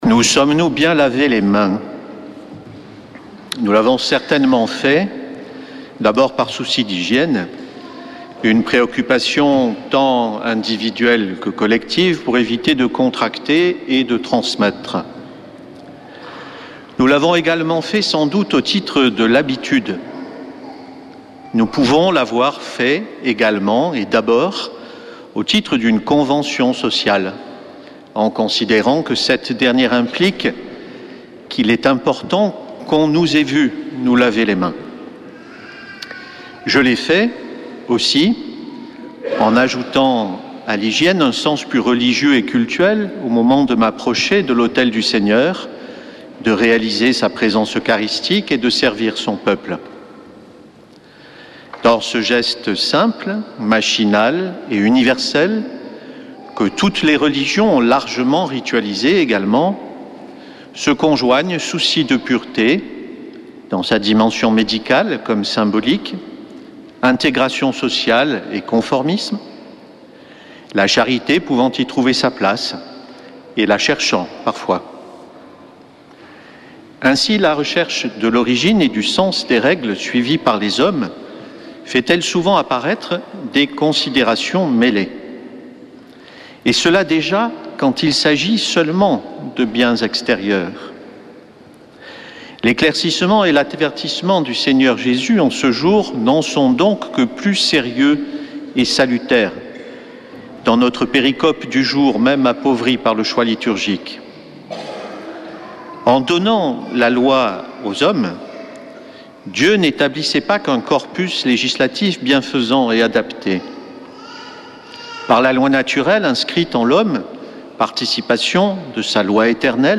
dimanche 1er septembre 2024 Messe depuis le couvent des Dominicains de Toulouse Durée 01 h 30 min
Homélie du 01 septembre